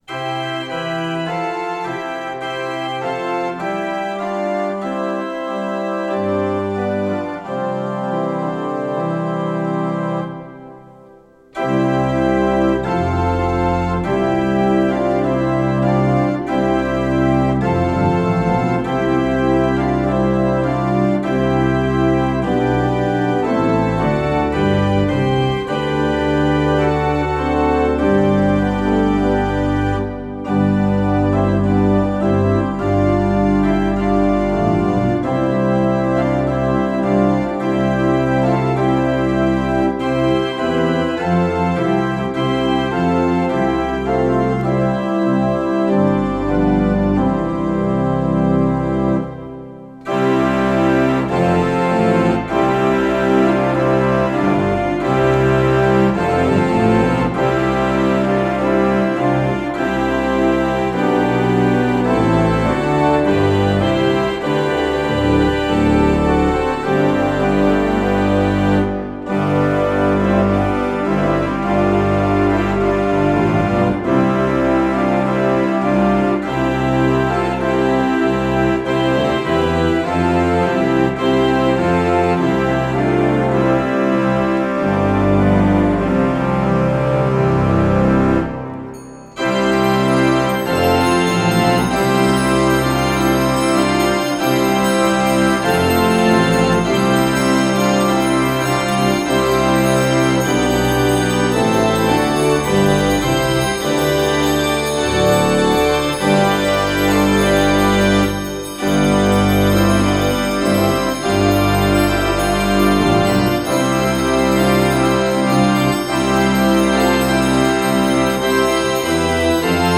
Choräle für Advent und Weihnachtszeit an Orgeln des Kirchenkreises